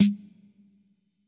Tag: drumcomputer 小鼓 葡萄酒